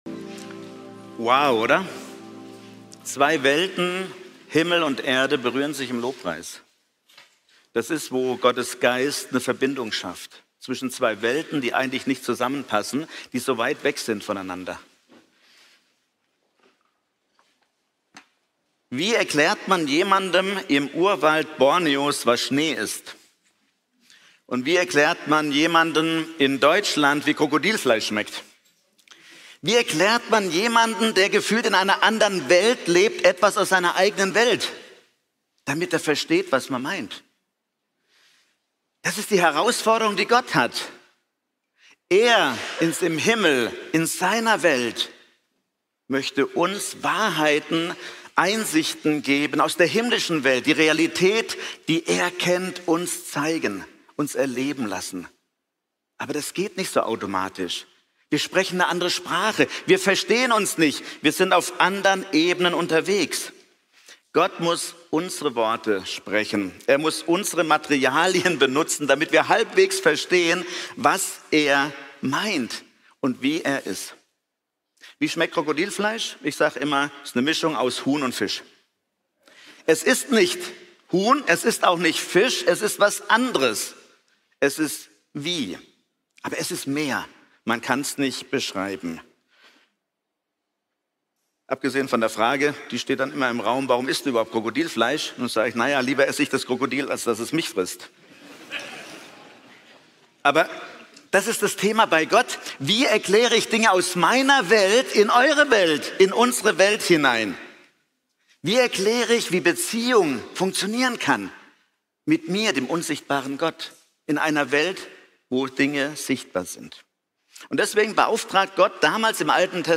Serie: Die Stiftshütte - Wo der Himmel die Erde berührt Typ: Predigt